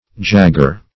Jagger \Jag"ger\ (j[a^]g"g[~e]r), n.